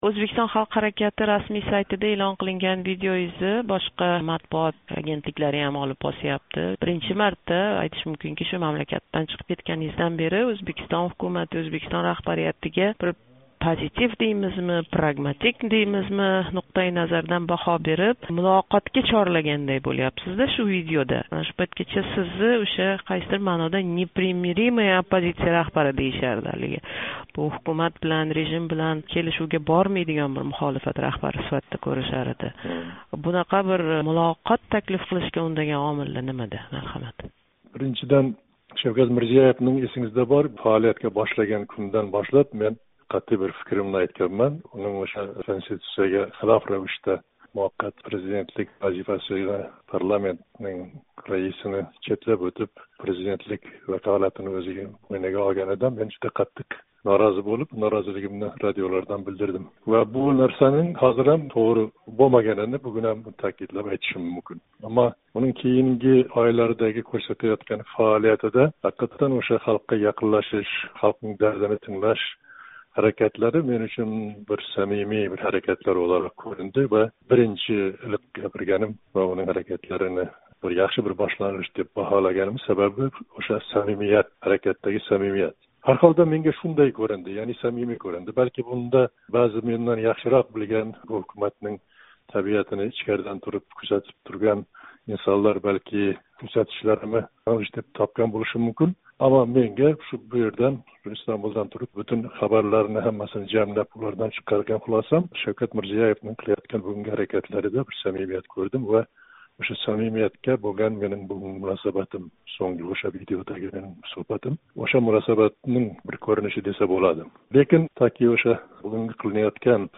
Муҳаммад Солиҳ билан суҳбат